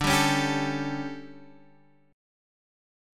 DM7sus4 chord